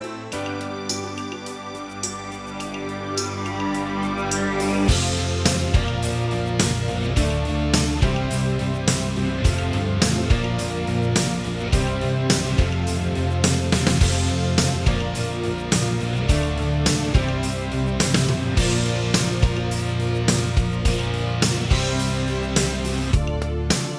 Minus All Guitars Pop (1980s) 3:55 Buy £1.50